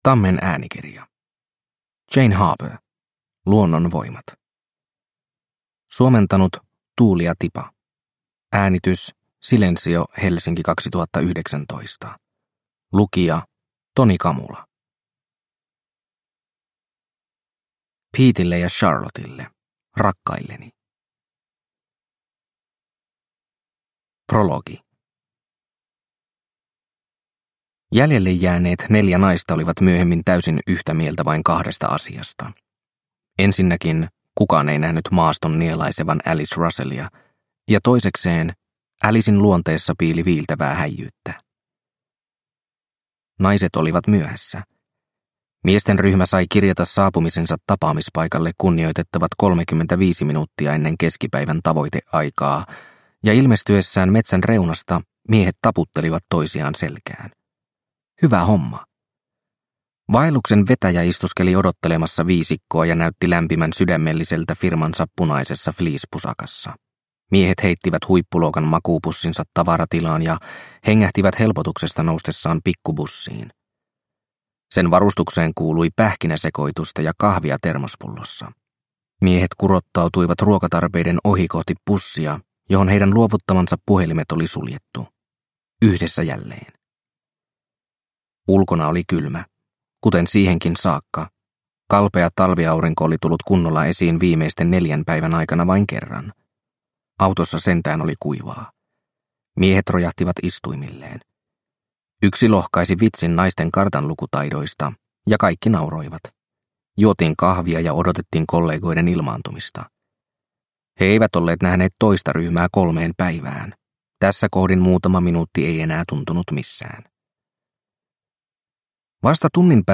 Luonnonvoimat – Ljudbok – Laddas ner